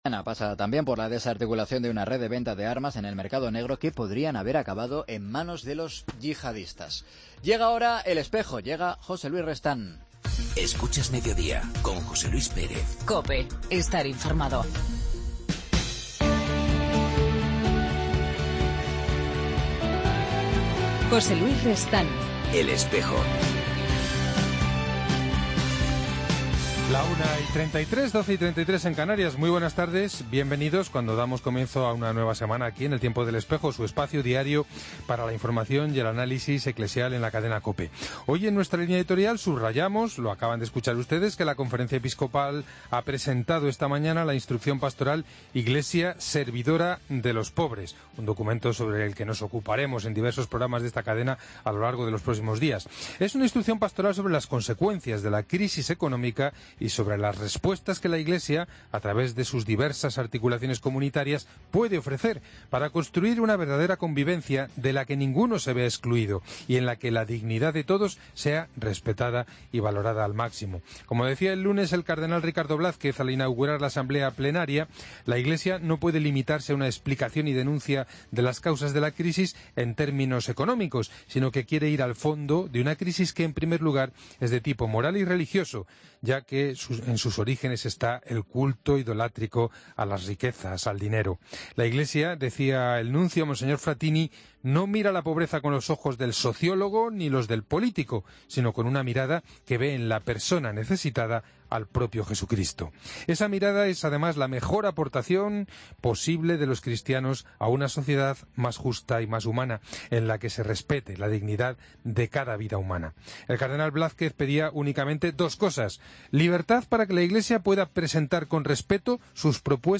AUDIO: Hoy en El Espejo entrevistamos al Arzobispo de Ayacucho y Presidente de la Conferencia Episcopal de Perú, Mons. Salvador Piñeiro, para...